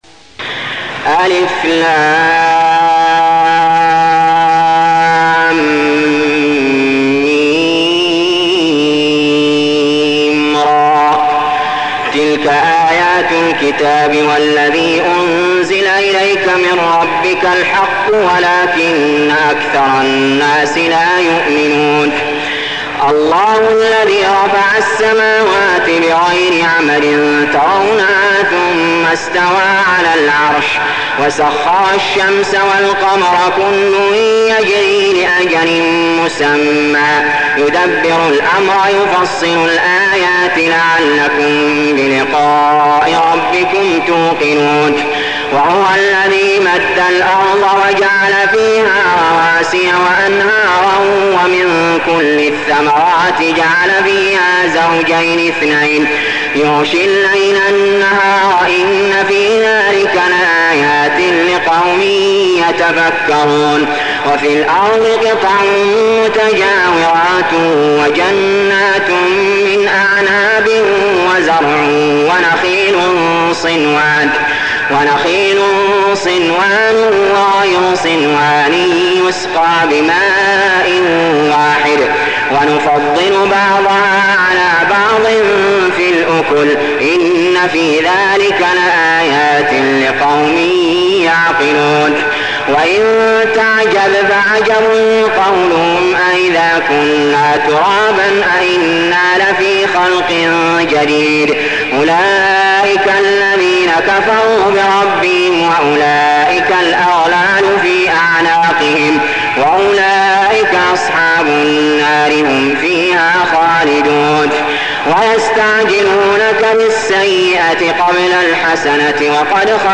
المكان: المسجد الحرام الشيخ: علي جابر رحمه الله علي جابر رحمه الله الرعد